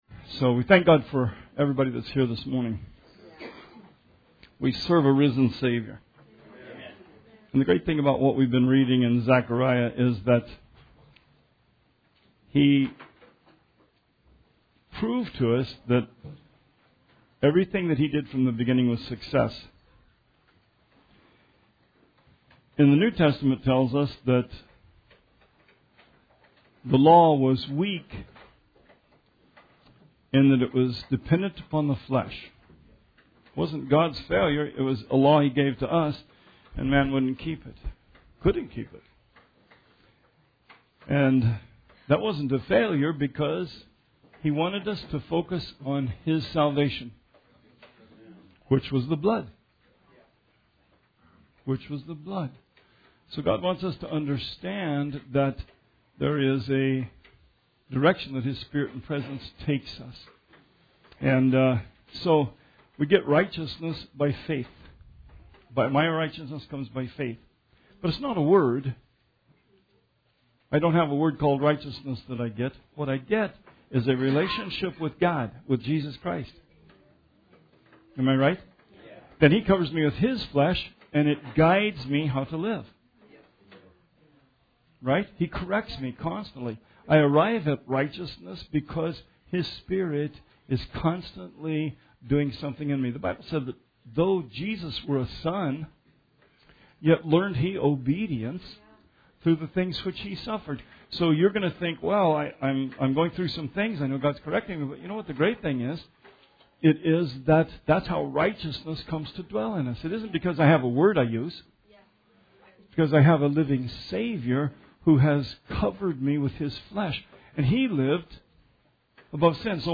Sermon 3/11/18